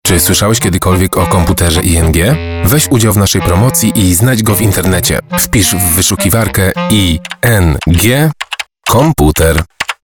Spot radiowy
ing_komputer_radio.mp3